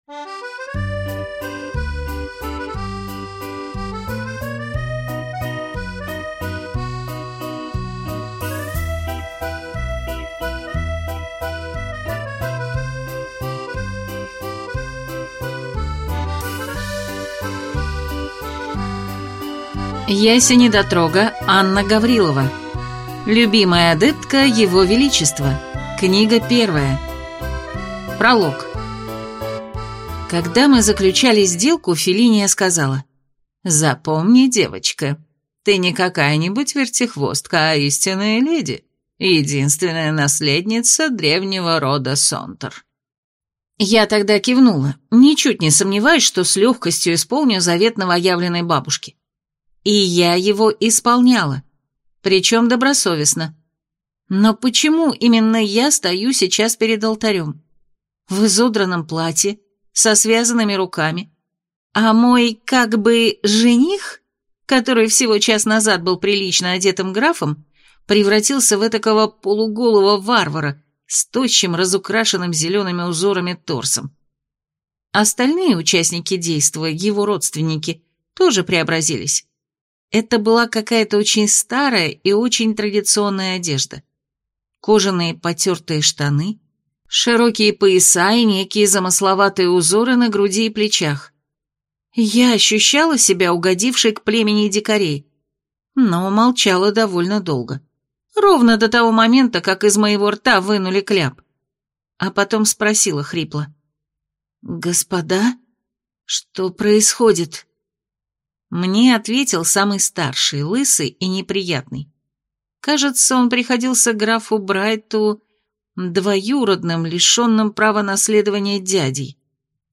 Аудиокнига Любимая адептка его величества. Книга 1 | Библиотека аудиокниг